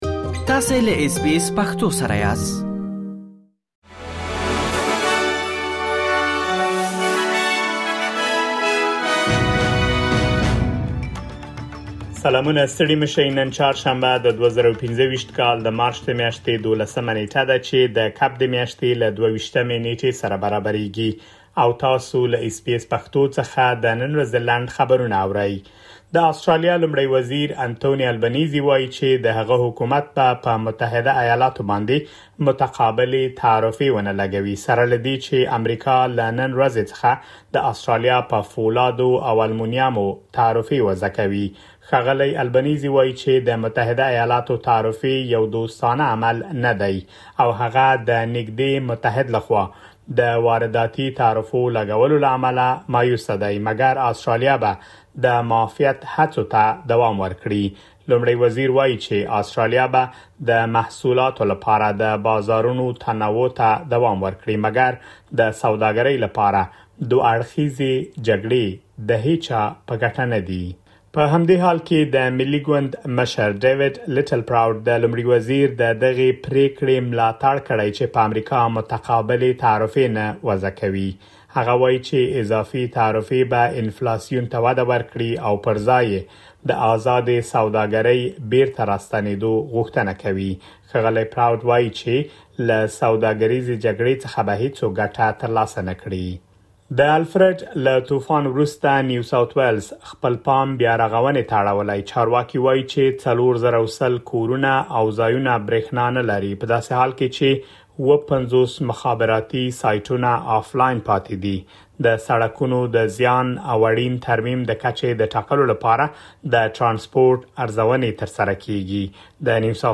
د اس بي اس پښتو د نن ورځې لنډ خبرونه | ۱۲ مارچ ۲۰۲۵